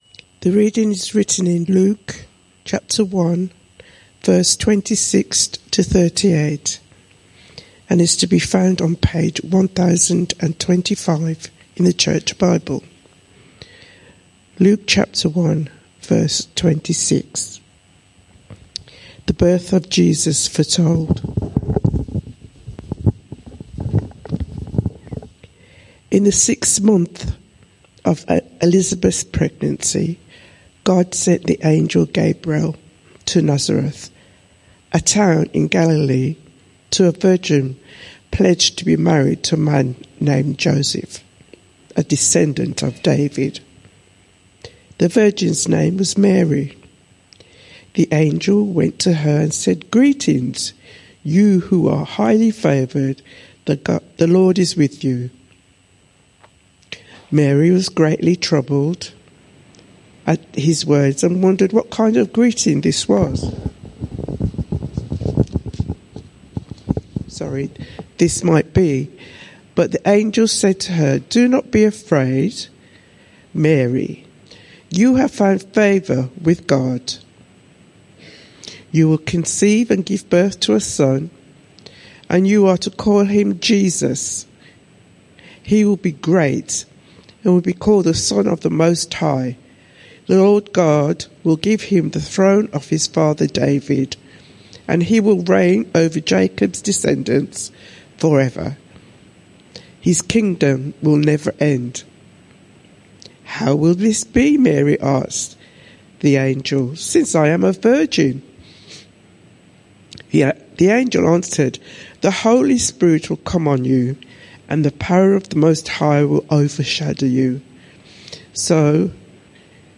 This sermon is part of a series: 7 December 2025, 10:30 am - Luke 1.26-38